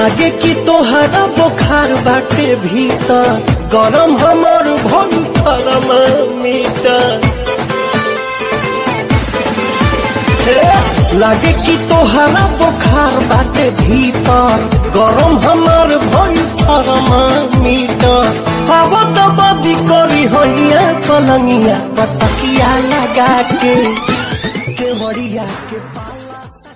Category: Bhojpuri Ringtones